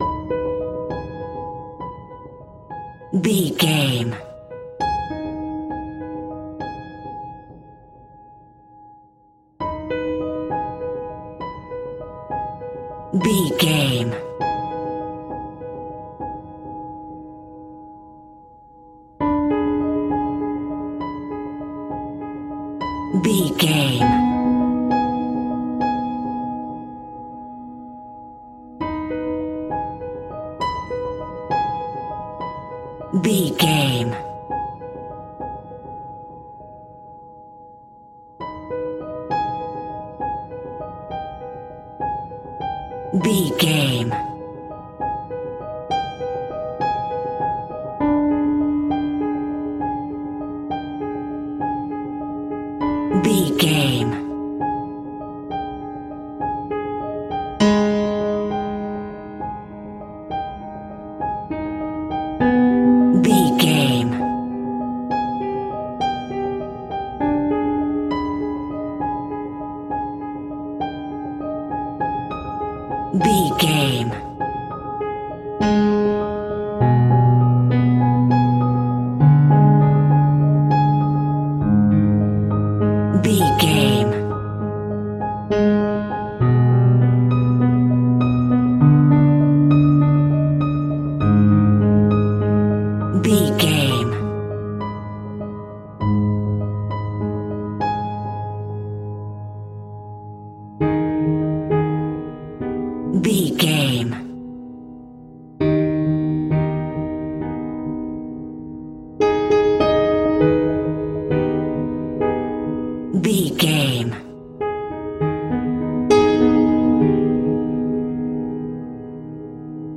Aeolian/Minor
A♭
ominous
eerie
horror music
horror piano